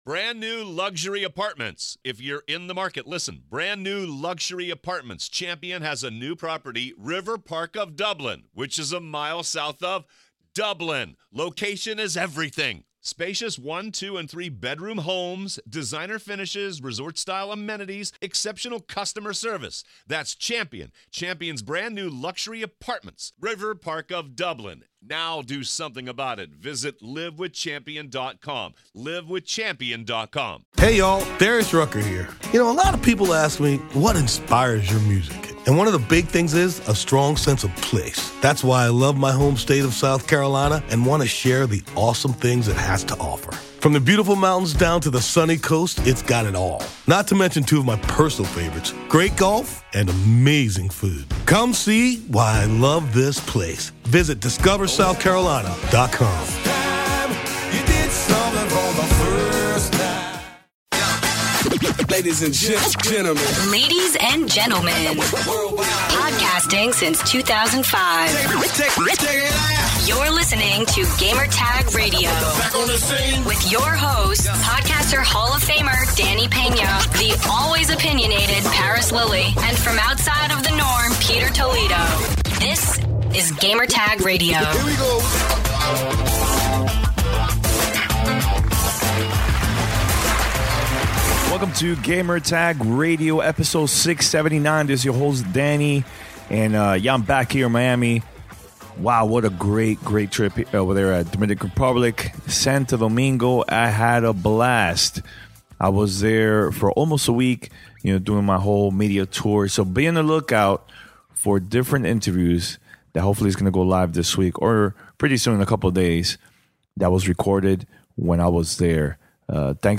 Part two of our special report live from Dominican Repubilc.